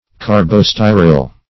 carbostyril - definition of carbostyril - synonyms, pronunciation, spelling from Free Dictionary
Carbostyril \Car`bo*sty"ril\, n. [Carbon + styrene.]